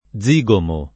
vai all'elenco alfabetico delle voci ingrandisci il carattere 100% rimpicciolisci il carattere stampa invia tramite posta elettronica codividi su Facebook zigomo [ +z&g omo ] (raro zigoma [ +z&g oma ]) s. m.; pl.